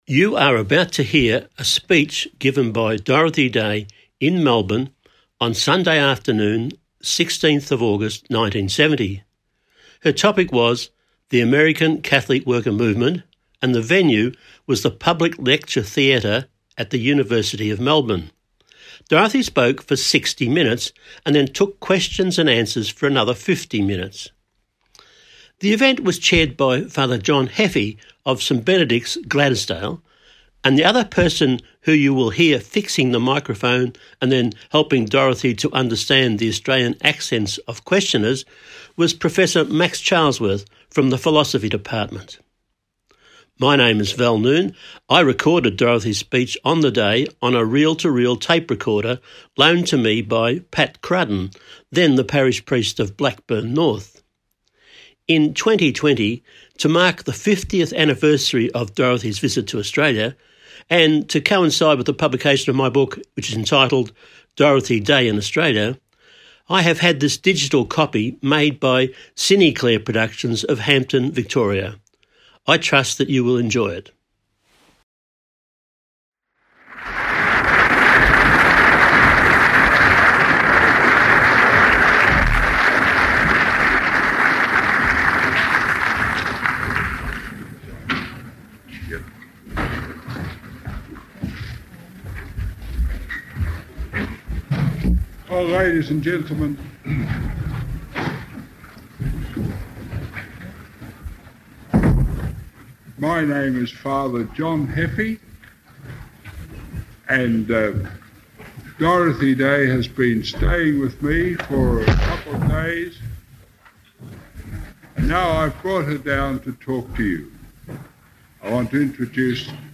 To mark this occasion I am, on his behalf, releasing an audio recording of Dorothy’s speech at the Public Lecture Theatre, University of Melbourne, on 16 August , 1970
The following recording gives a rare and precious insight into her personality and mission. (There are some difficulties in the early minutes of the tape.)